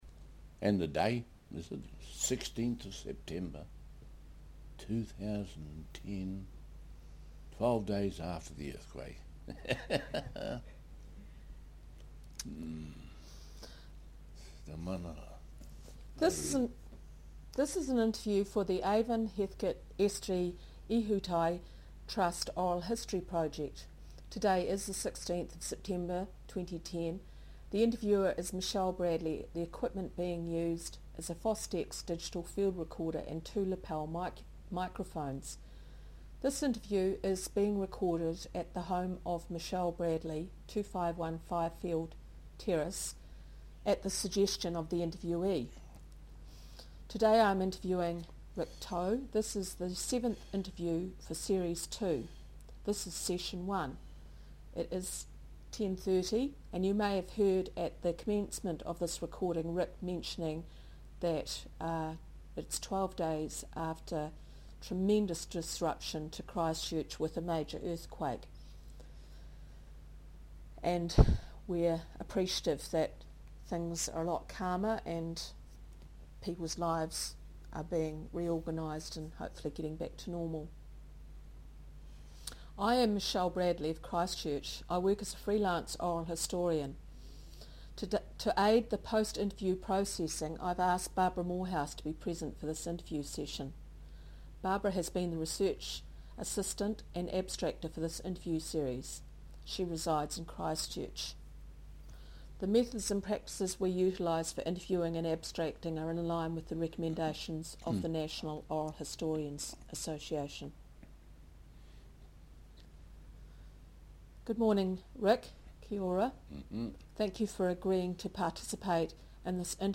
Part of the Avon-Heathcote Estuary Ihutai Trust 2010 Oral History Series.